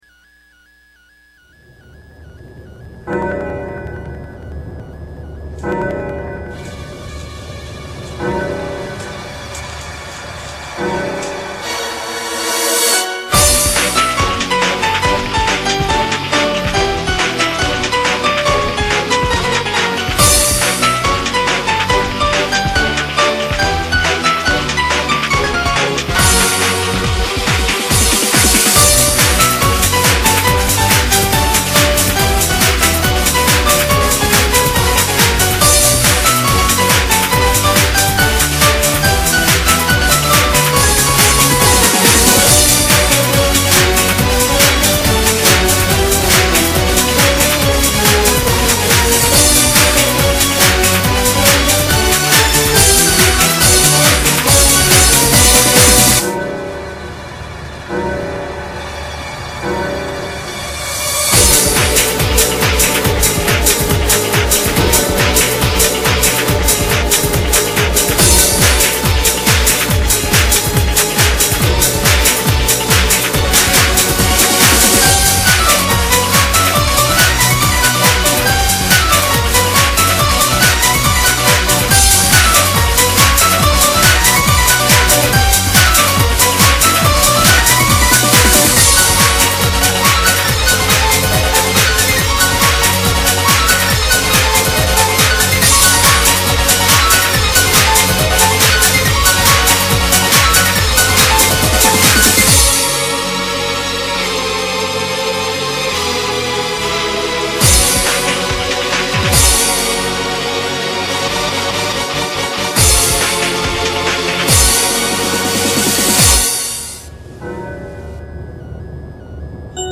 역시 퓨젼 곡은 아니지만 피아노 선율이 멋지네요.